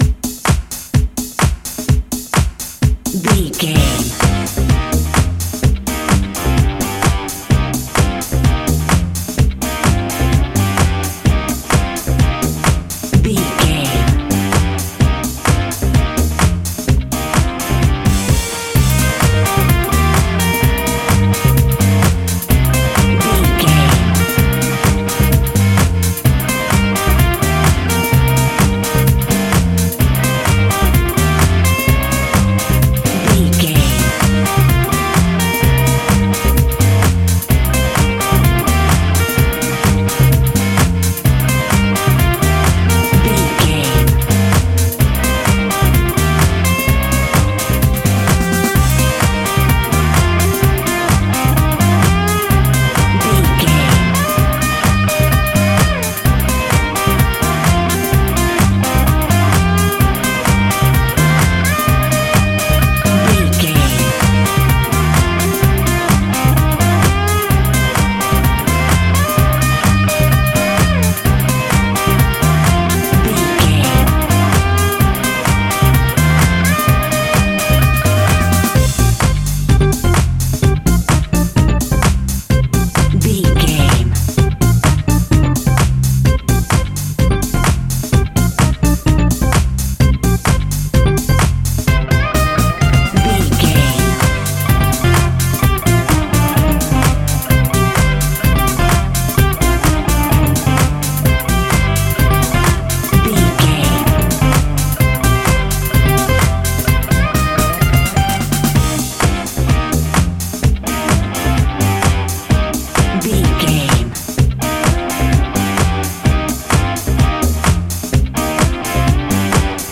Aeolian/Minor
groovy
uplifting
driving
energetic
bass guitar
electric guitar
drums
synthesiser
electric piano
instrumentals